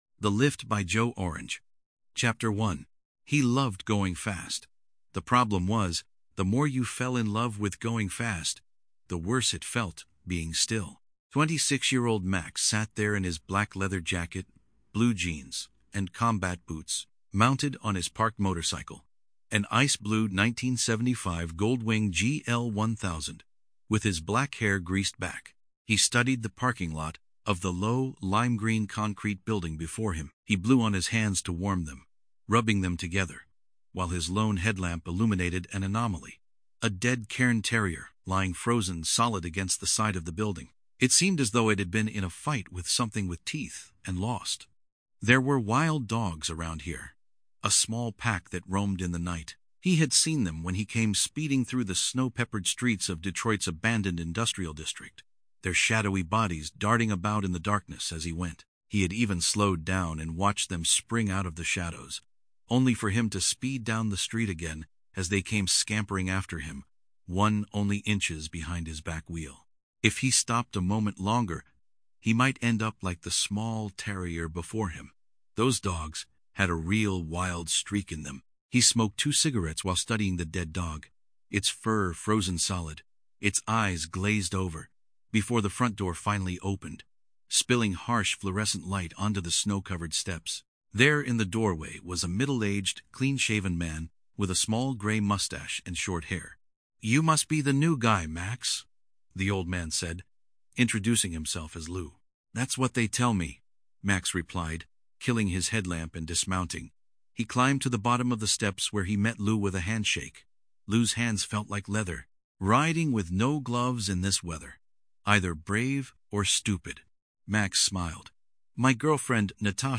ePub + PDF + Audiobook (3hr 16min)